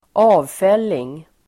Uttal: [²'a:vfel:ing]